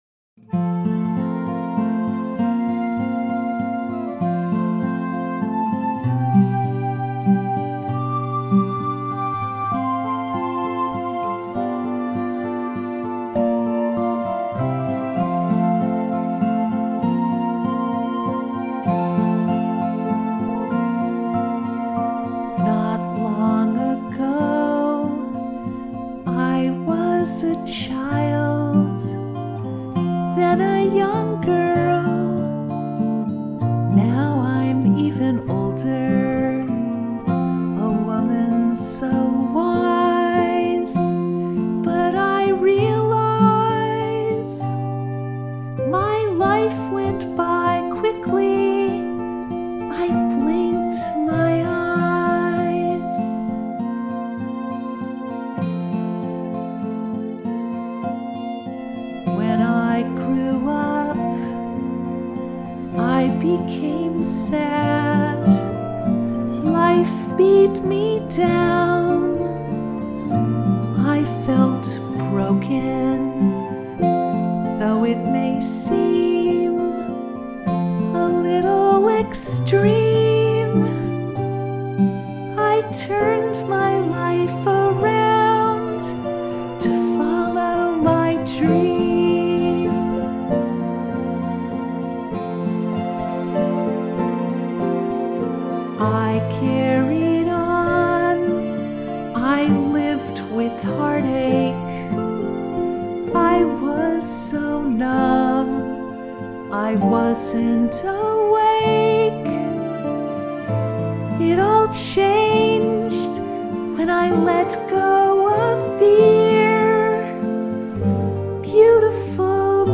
Let these three heartfelt vocal songs inspire you with their touching messages.